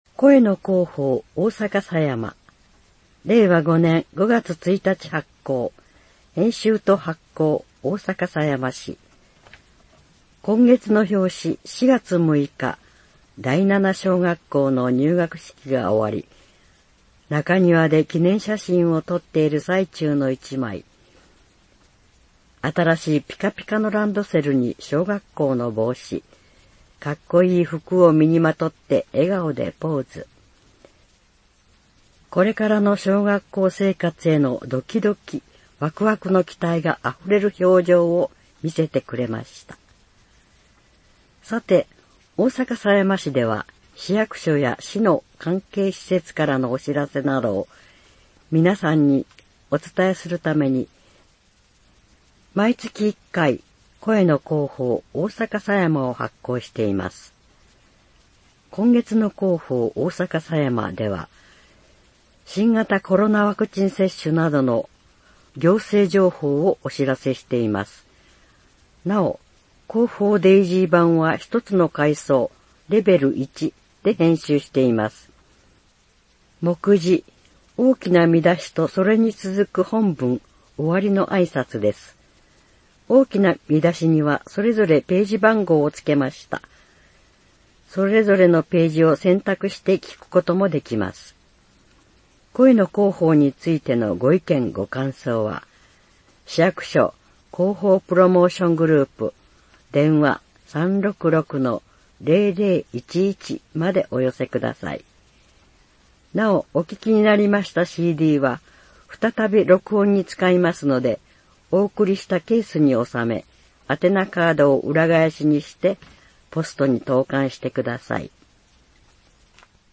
大阪狭山市では、視覚に障がいのある人を対象にした音声の広報誌「声の広報」を発行しています。これは、「広報おおさかさやま」の内容をCDに収録したものです。